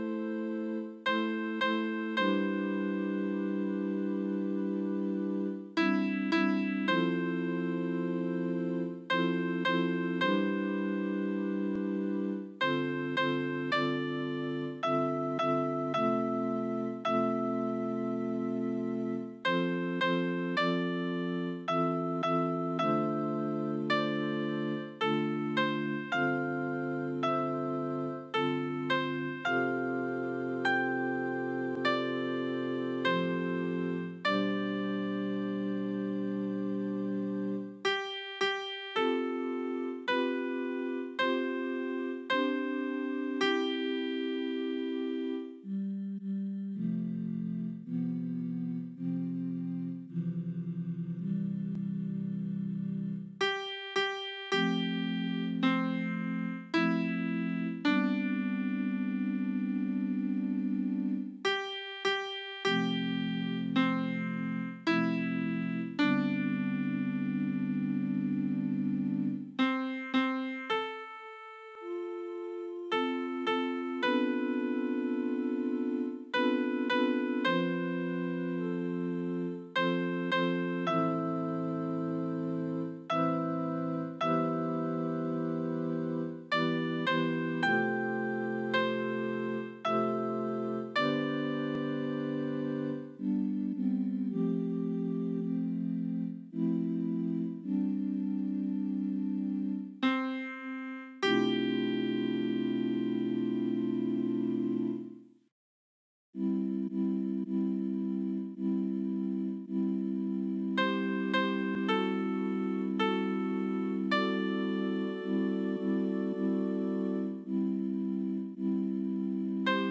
Versions piano
SOPRANO 1